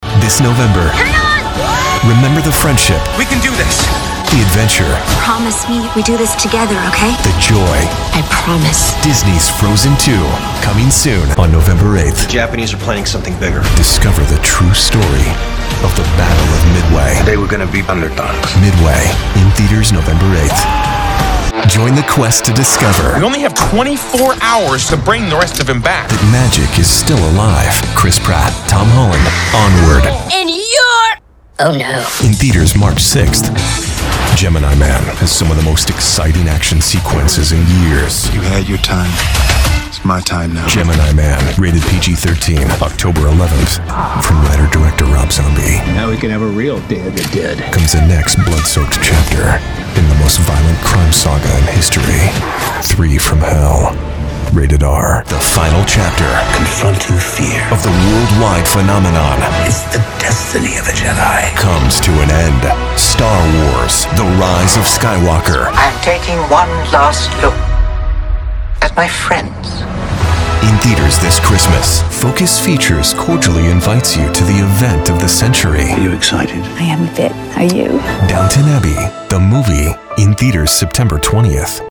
Young Adult, Adult
standard us | natural
cinema trailer